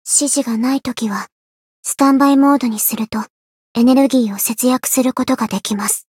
灵魂潮汐-阿卡赛特-闲聊-不开心.ogg